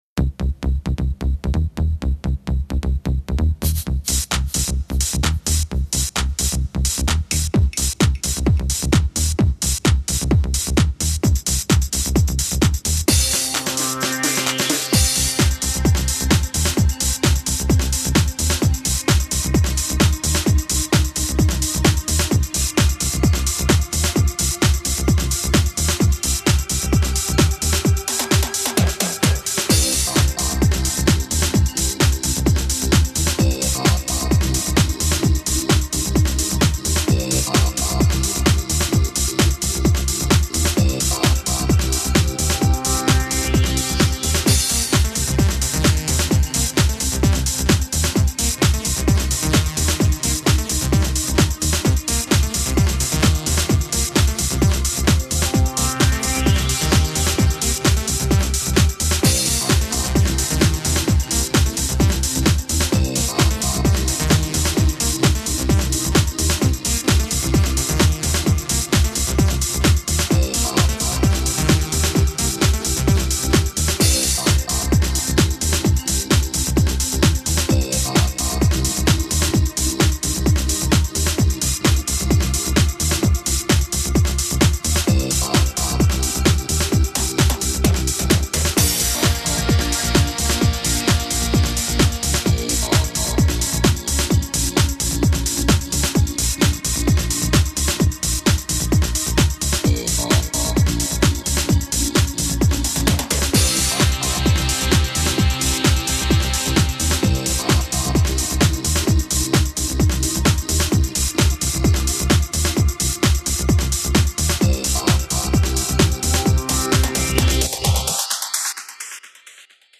DISASTER VIDEO MUSIC